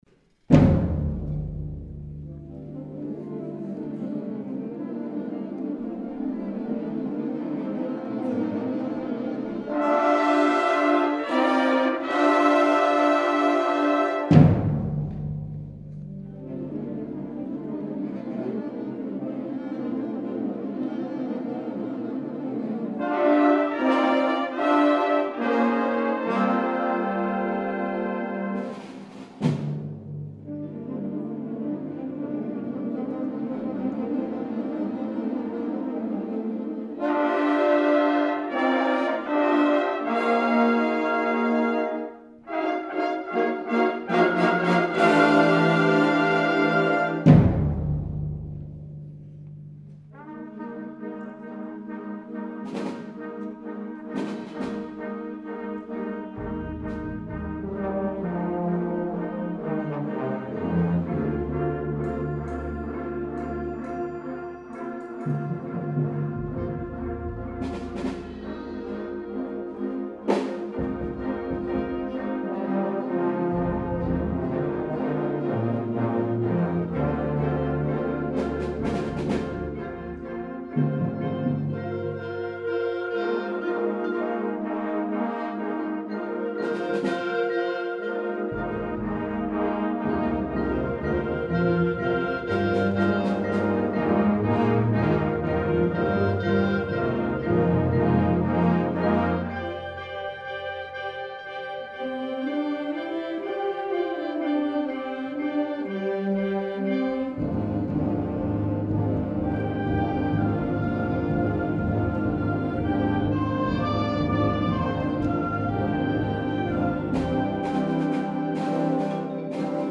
2013 Winter Concert